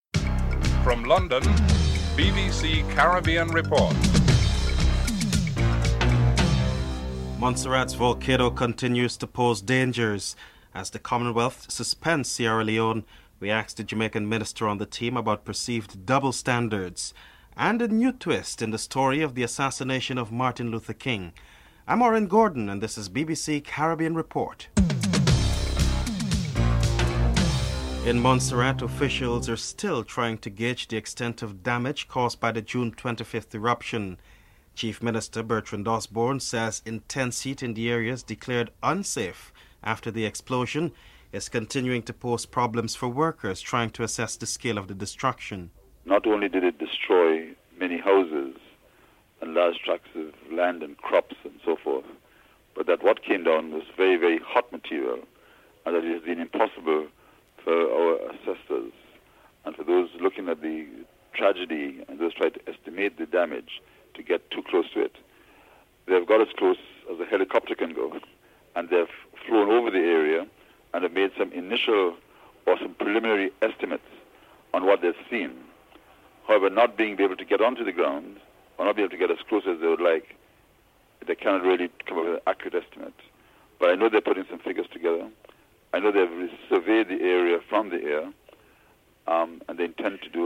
The British Broadcasting Corporation
1. Headlines (00:00-00:27)
Seymour Mullings, Jamaica's Foreign Minister is interviewed (03:21-06:57)